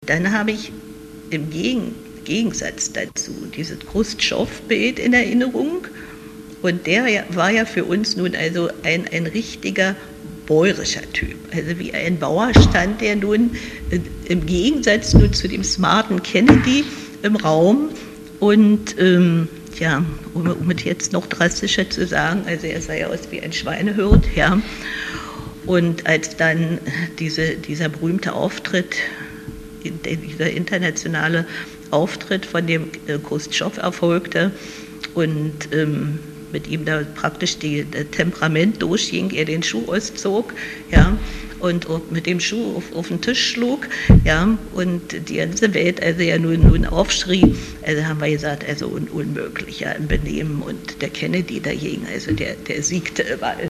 Obwohl beide unzufrieden mit der politischen Führung der DDR waren, zeigten sie in einem Interview auf Fragen zum Kennedy-Besuch sehr unterschiedliche Meinungen.